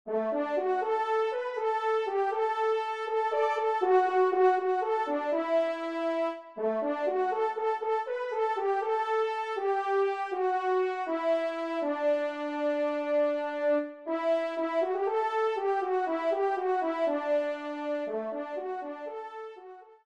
Solo Ton Simple